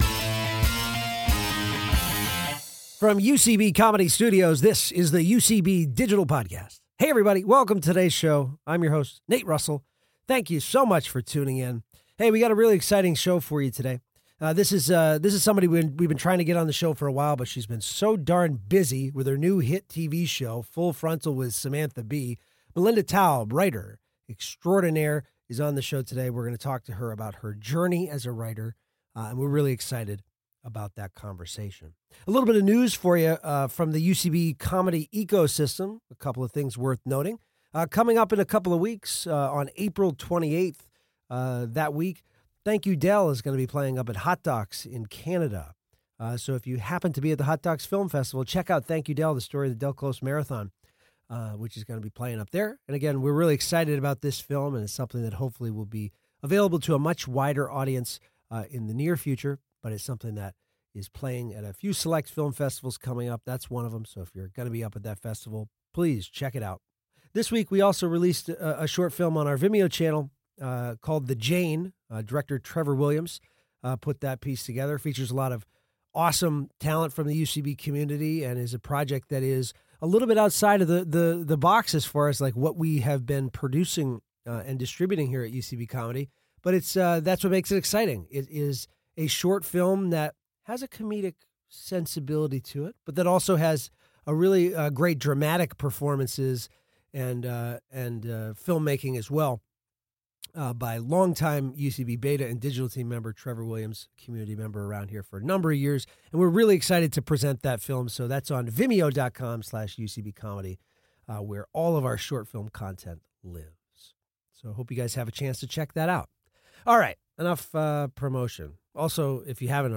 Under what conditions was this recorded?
Recorded at UCB Comedy Studios East in New York City.